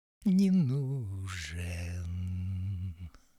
Щелчков, скрипов, испорченных сибилянтов...